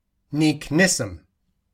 with the imaginary name Neak Nissom /niːk ˈnɪsəm/: